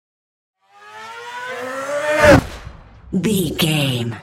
Whoosh fast engine speed
Sound Effects
futuristic
intense
whoosh
car